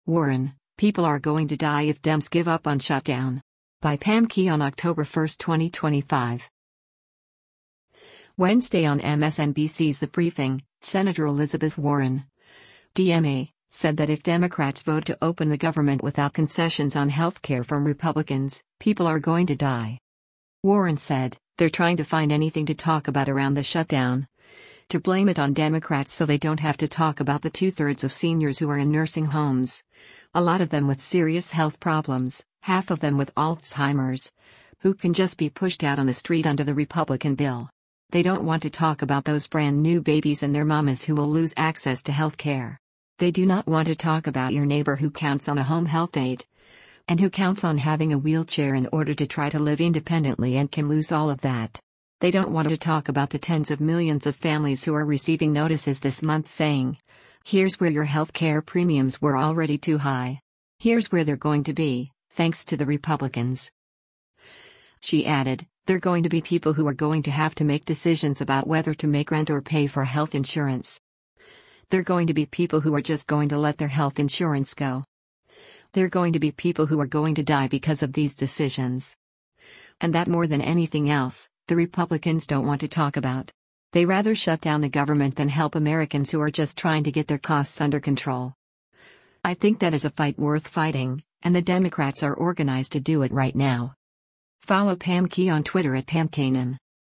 Wednesday on MSNBC’s “The Briefing,” Sen. Elizabeth Warren (D-MA) said that if Democrats vote to open the government without concessions on healthcare from Republicans, people “are going to die.”